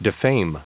Prononciation du mot defame en anglais (fichier audio)
Prononciation du mot : defame